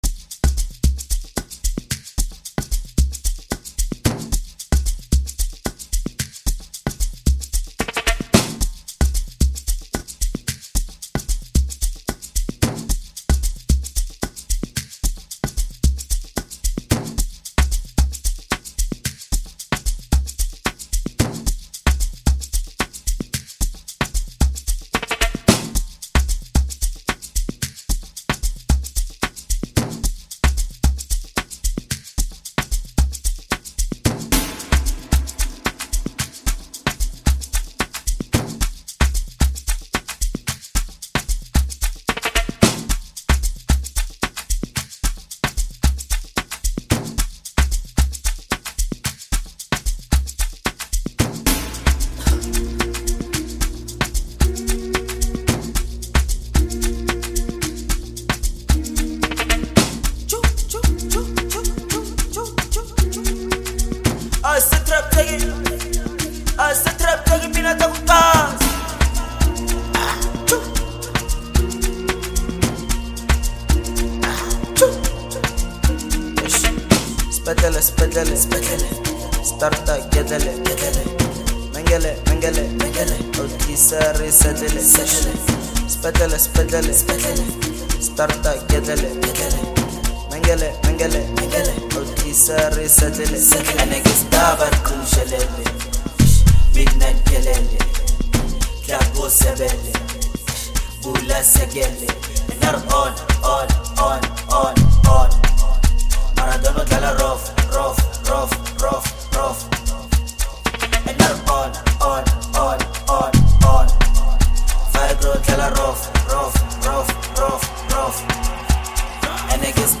South African Amapiano maestro